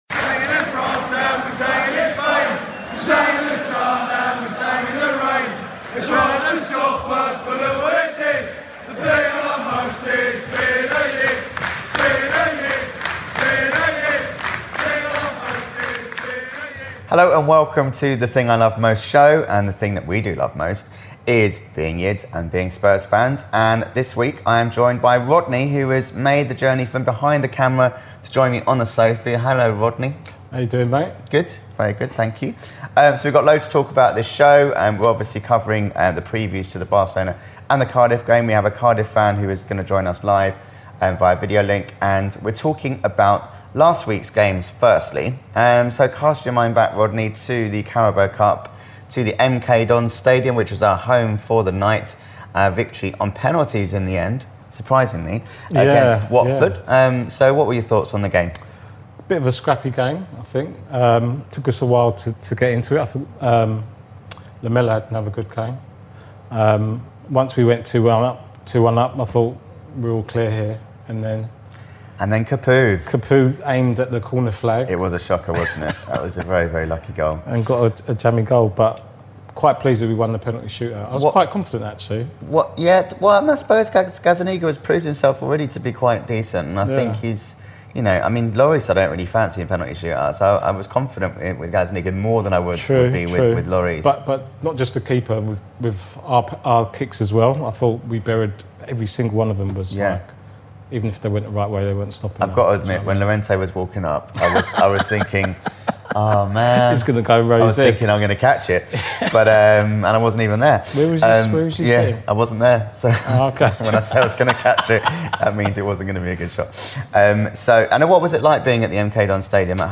in the studio to reflect on the victories against Watford and Huddersfield, where we analyse team selection, formation and player form.
on video call.